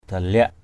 daliak.mp3